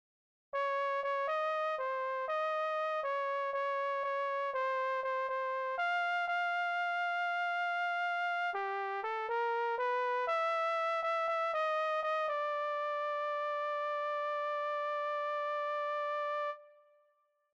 Key written in: B♭ Major
Type: Barbershop
Each recording below is single part only.